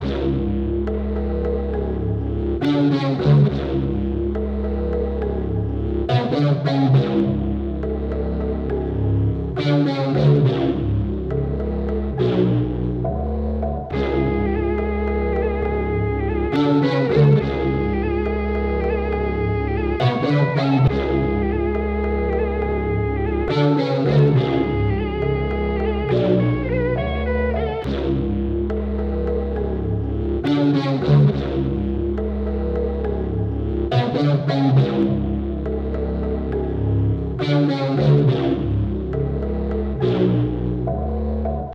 monster_69bpm_oz.wav